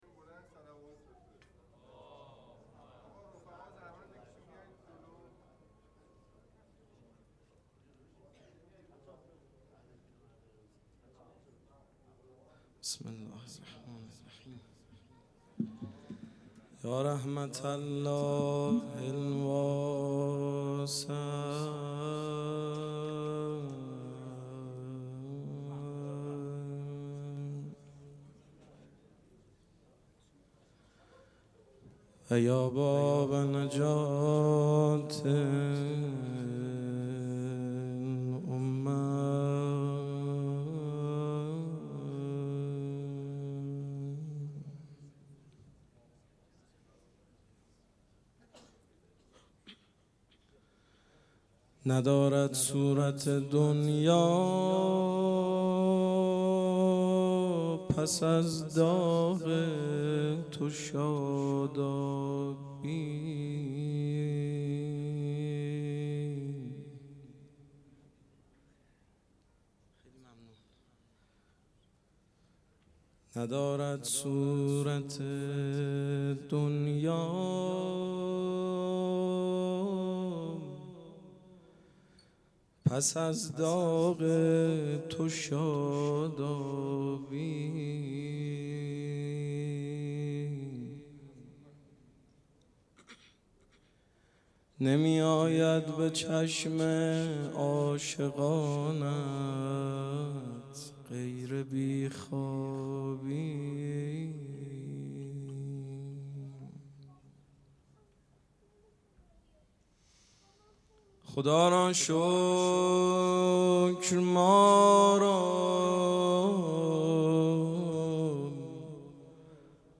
مراسم عزاداری شام شهادت حضرت رقیه سلام الله علیها